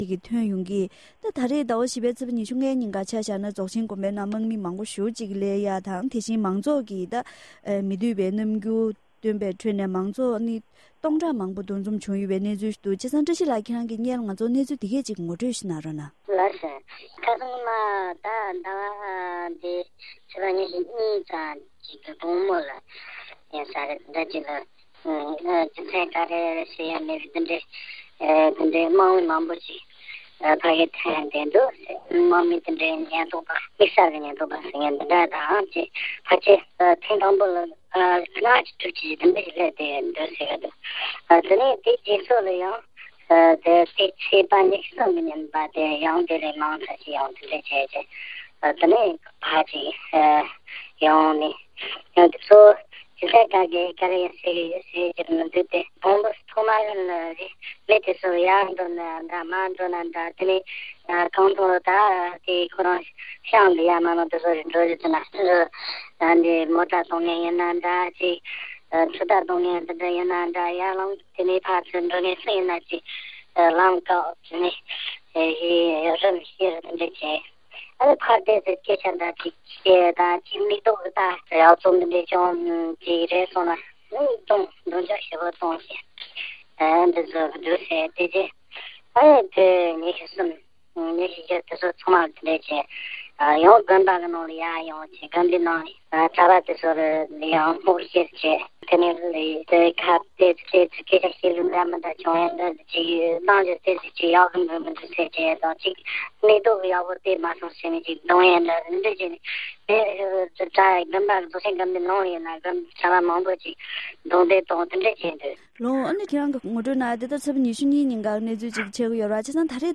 བོད་ཁམས་ཁུལ་དུ་ཡོད་པའི་བོད་མི་ཞིག་དང་བཀའ་མོལ་ཞུས་པ་ཞིག་གསན་རོགས་གནང༌༎